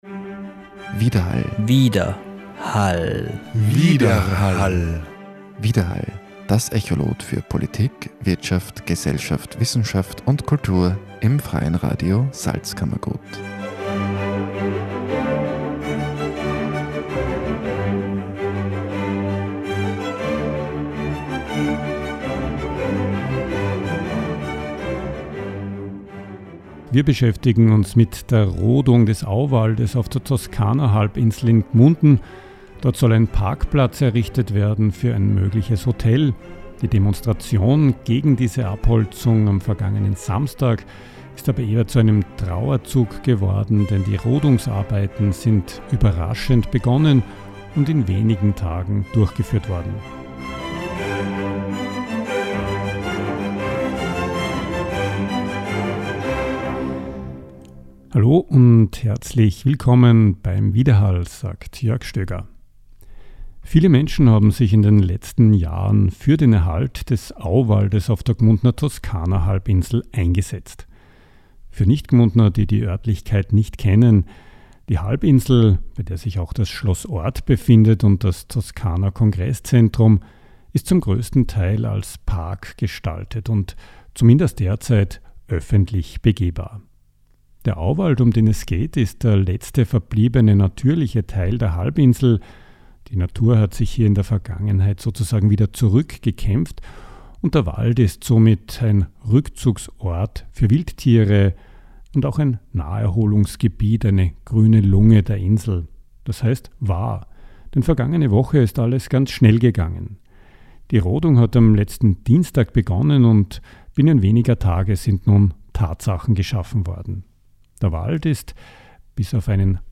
Dazu ein Interview